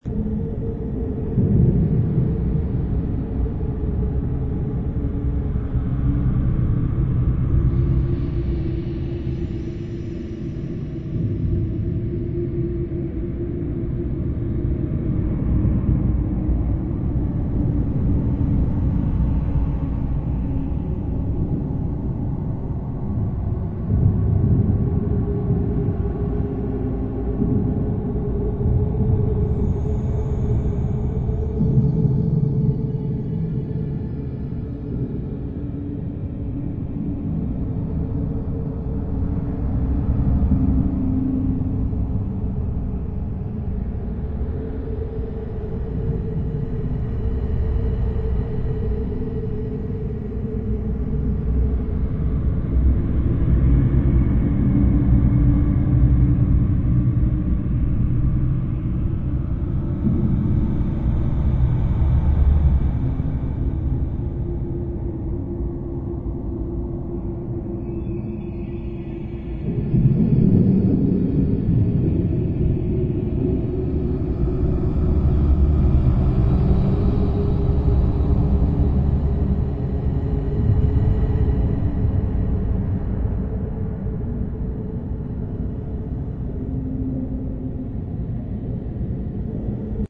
zone_field_asteroid_mine.wav